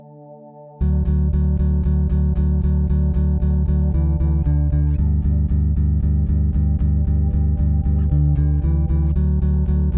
V normálních podmínkách - kytara , krkový snímač, hráno na strunách A, E.
Basa s oktáverem, tenhle hnusnej zvuk poznám na 100 honů.
Mě to zní jako nějakej basovej sample naprogramovanej i v oktávě.
Ta první ukázka má každopádně ten hlubší tón furt stejnej.
...no je to takový dost syntetický. roll